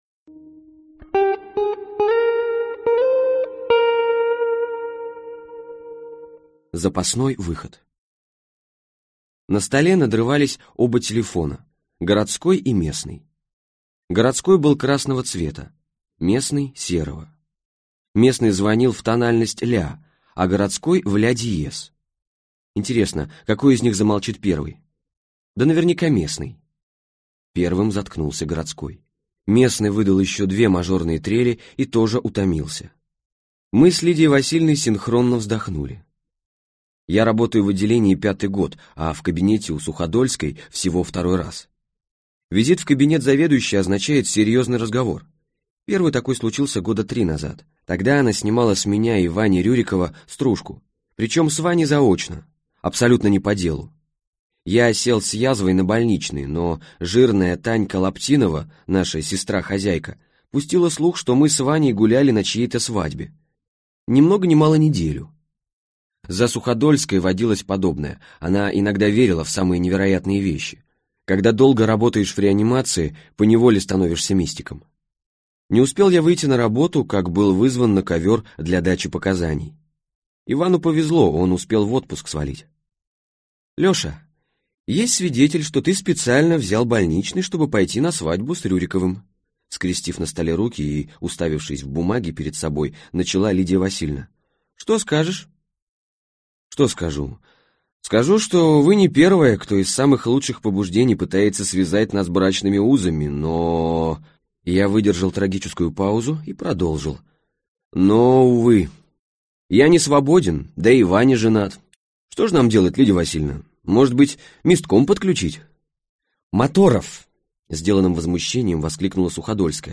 Аудиокнига Юные годы медбрата Паровозова | Библиотека аудиокниг